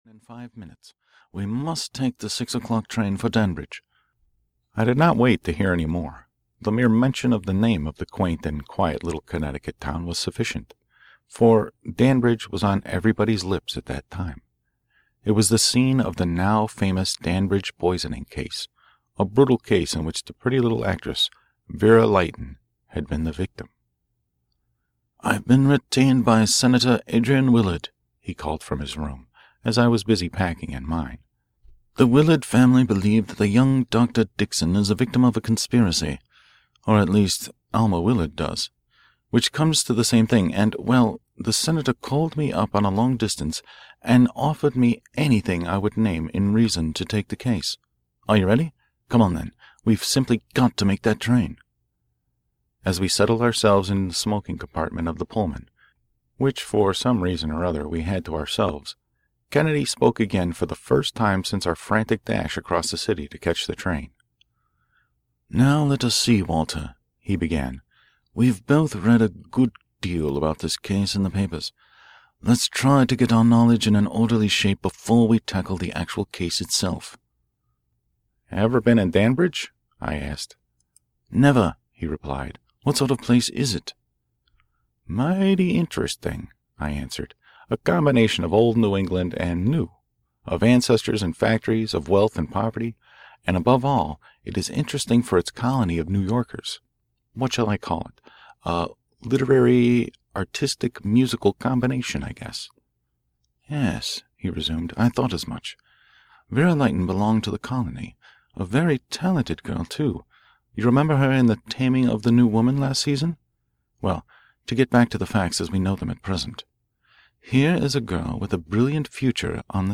The Poisoned Pen (EN) audiokniha
Ukázka z knihy